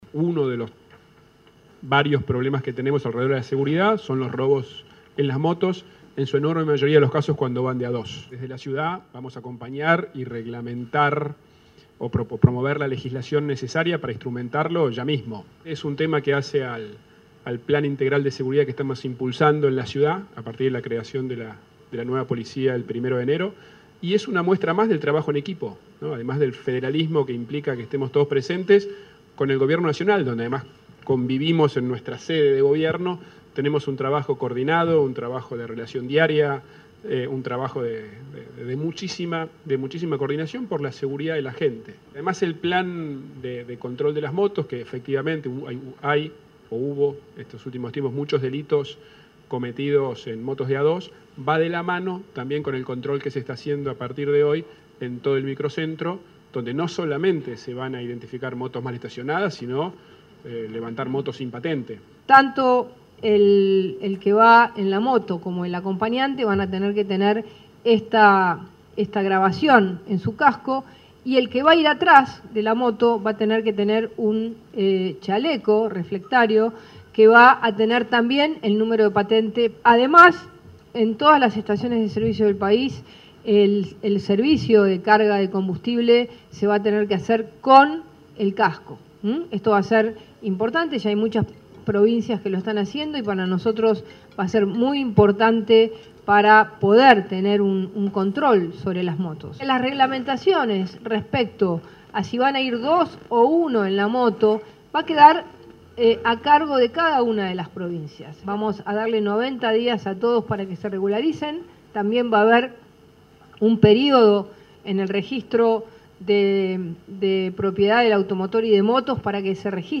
El jefe de Gobierno de la Ciudad de Buenos Aires, Horacio Rodríguez Larreta, y la ministra de Seguridad de la Nación, Patricia Bullrich, anunciaron la modificación del Decreto Reglamentario de la Ley Nacional de Tránsito a través de la cual se busca tener un mayor control para poder identificar a quienes utilizan las motos para cometer delitos.
Del anuncio también participaron el ministro de Transporte de la Nación, Guillermo Dietrich; el vicejefe de Gobierno porteño, Diego Santilli; el ministro de Seguridad bonaerense, Cristian Ritondo, y otros ministros provinciales.